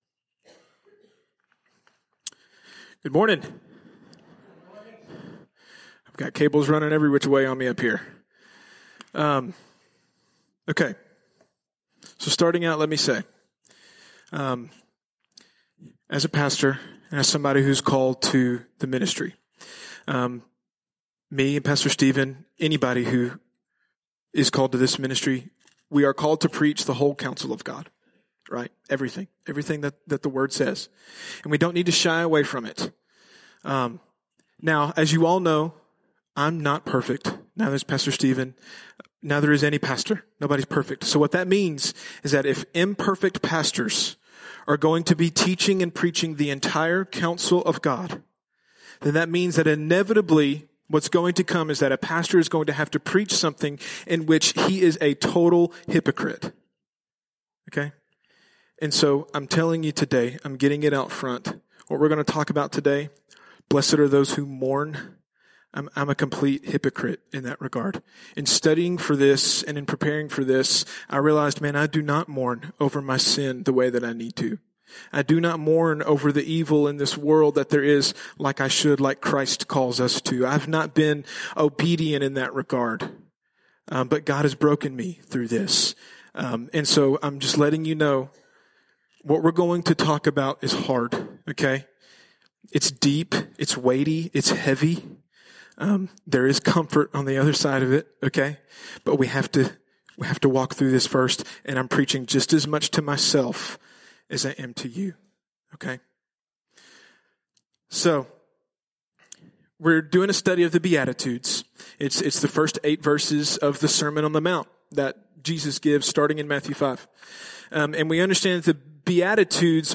Misc. Sermons